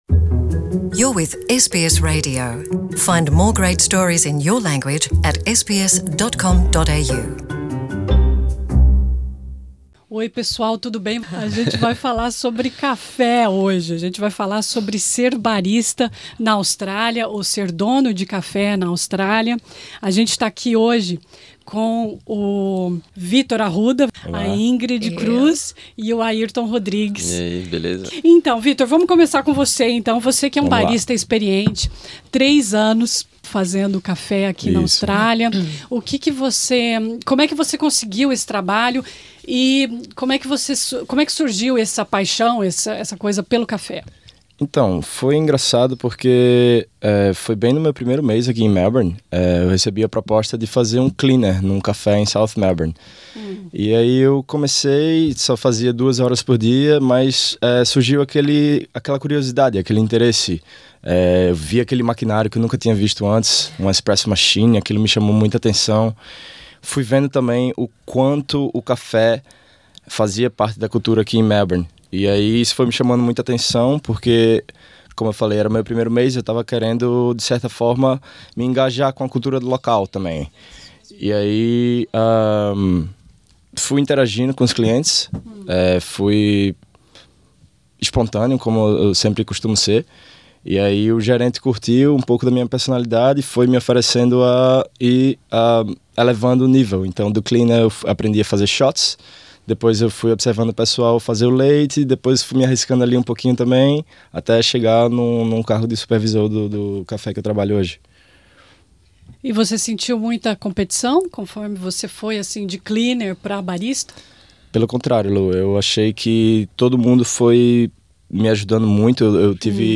Nessa conversa informal
nos estúdios da SBS